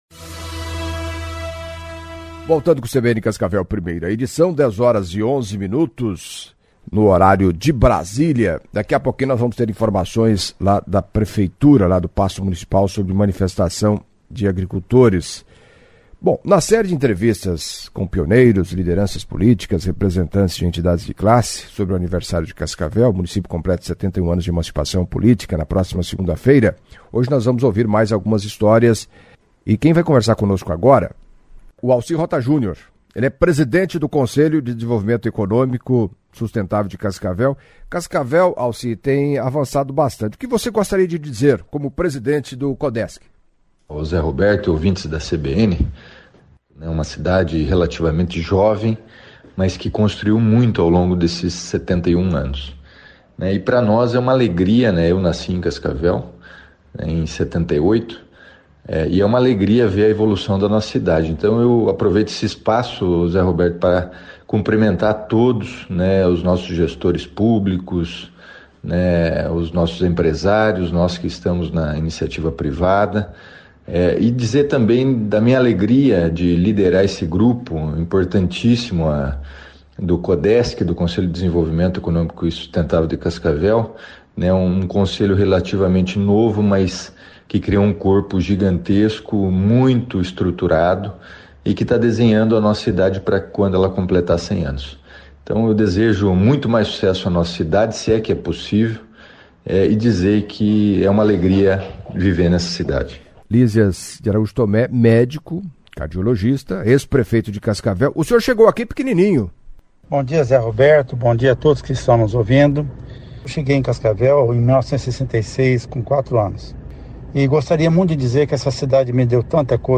A CBN segue ouvindo pioneiros, lideranças políticas e representantes de entidades de classe, sobre o aniversário de Cascavel. O município completa na segunda-feira, 14 de novembro, 71 anos de emancipação política.